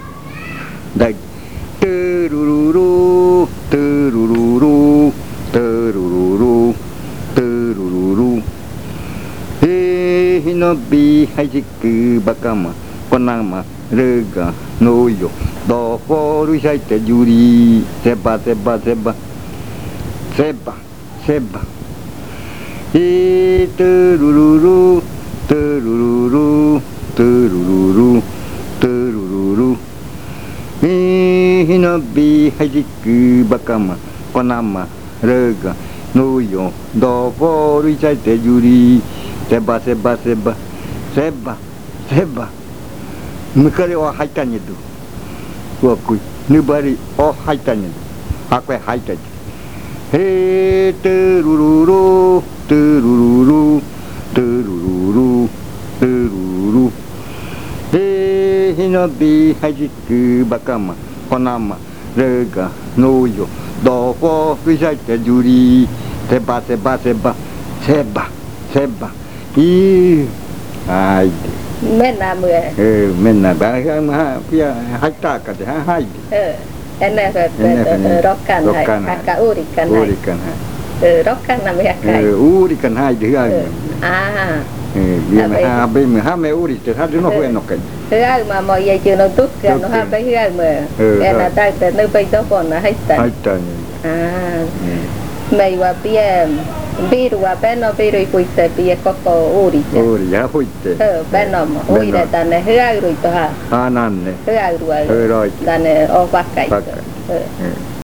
Leticia, Amazonas
Canto hablado (uuriya rua).
Spoken chant (uuriya rua).